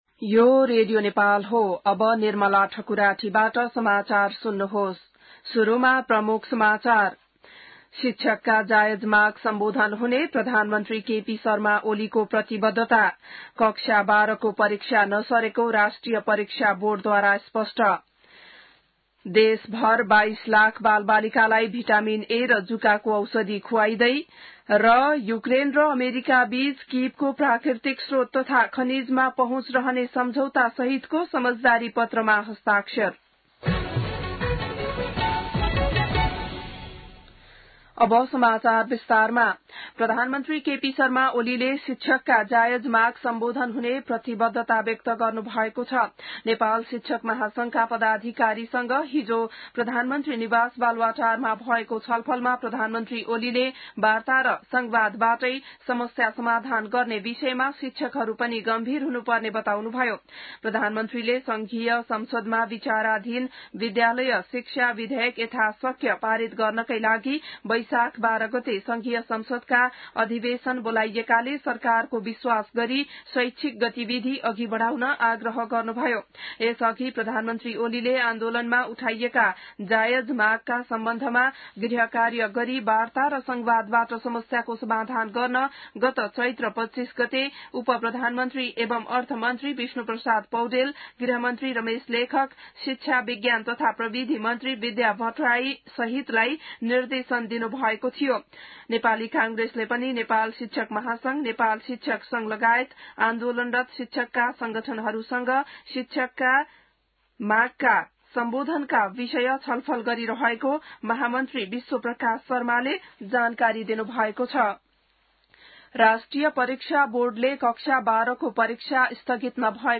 बिहान ९ बजेको नेपाली समाचार : ६ वैशाख , २०८२